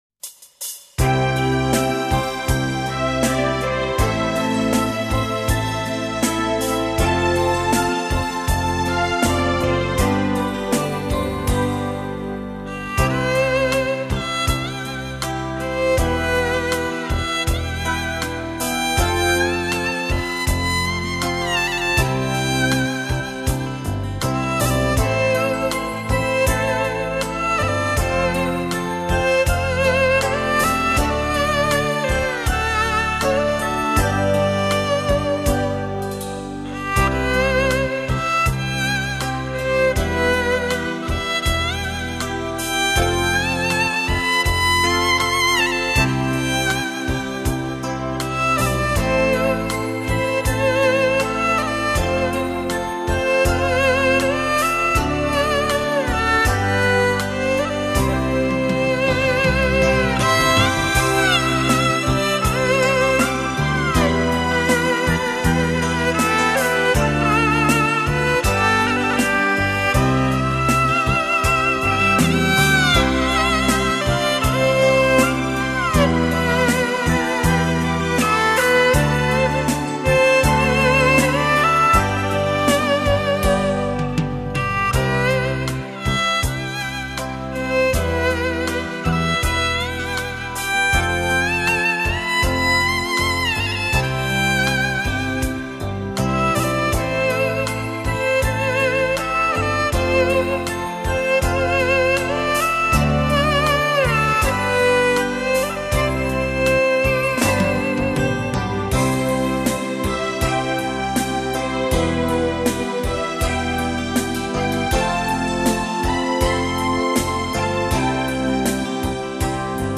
最美妙的旋律 柔情蜜意的二胡 让经典伴你一路同行